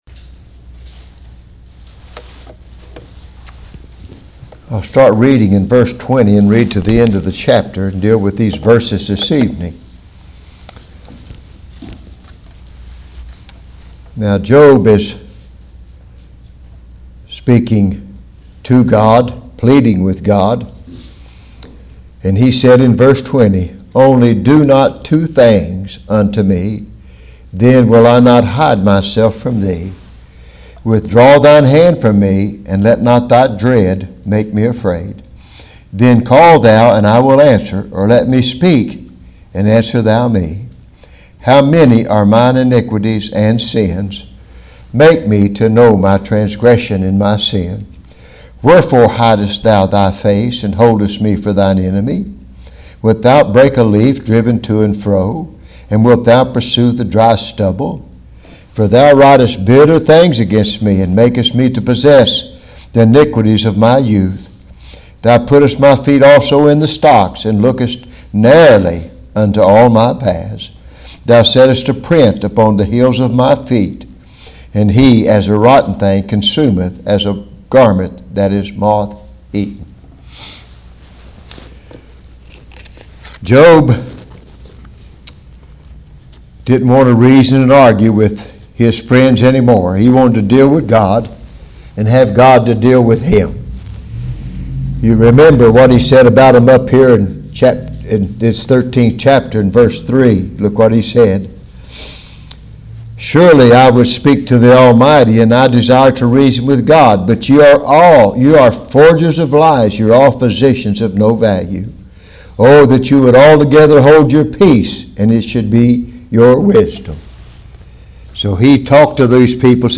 Job pleads with God | SermonAudio Broadcaster is Live View the Live Stream Share this sermon Disabled by adblocker Copy URL Copied!